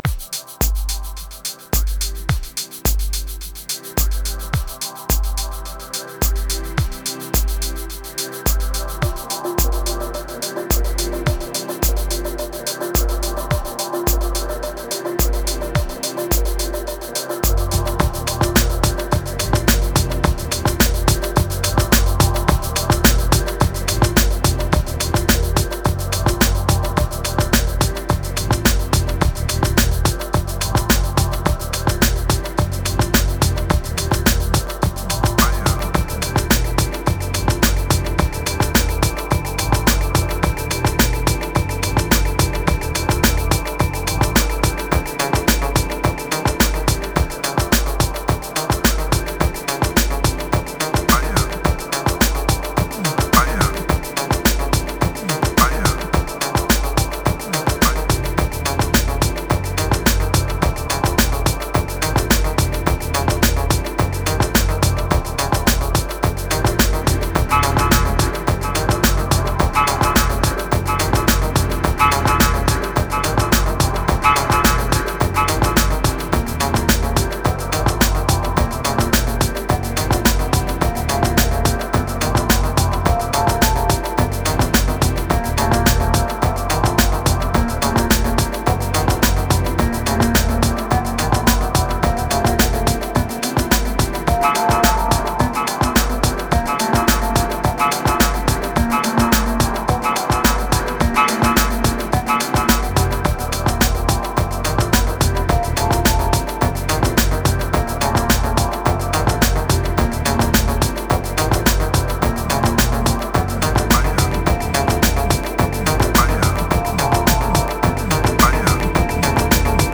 1281📈 - 96%🤔 - 107BPM🔊 - 2024-02-01📅 - 955🌟
Dub Electro Uplifting Orb Energy Kicks Beat